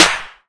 CDK Sauce Snare.wav